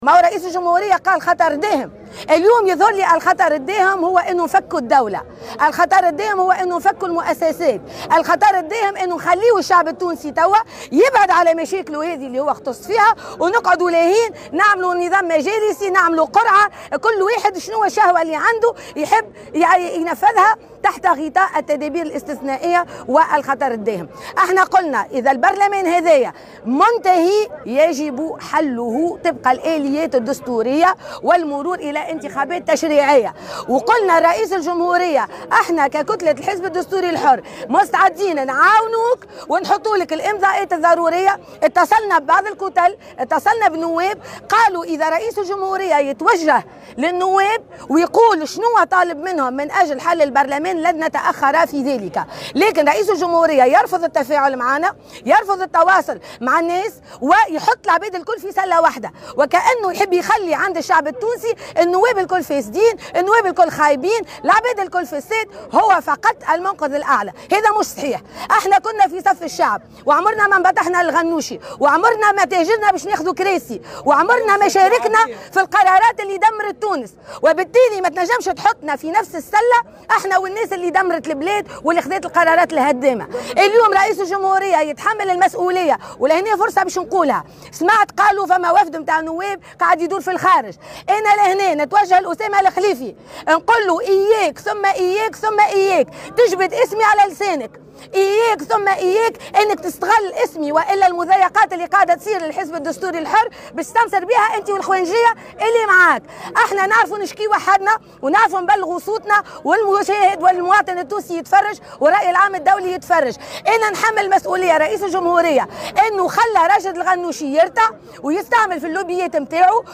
اعتبرت رئيسة الحزب الدستوري الحر عبير موسي، في تصريح لمراسل الجوهرة أف أم، أنه يجب حل البرلمان المجمد طبقا للآليات الدستورية، والمرور إلى انتخابات تشريعية مبكرة، منتقدة رفض رئيس الجمهورية التواصل والتفاعل مع مكونات المشهد السياسي، ووضعه لكافة الاطياف السياسية في سلّة واحدة، بمن فيهم الحزب الدستوري الذي لم يشارك في القرارات التي دمّرت البلاد، وفق تعبيرها.